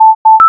cheese-egg-click.wav